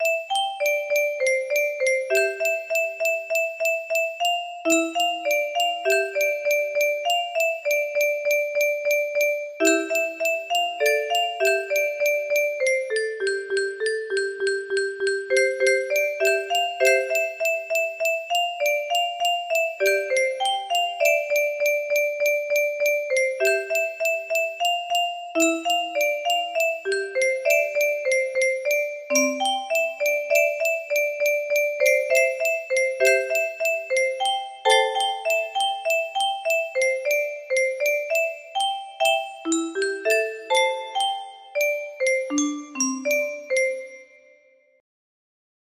Clone of Leo shorter music box melody
Imported from MIDI from imported midi file (9).mid